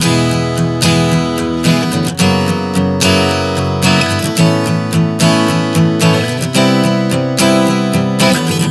Prog 110 E-F#m11-A-C.wav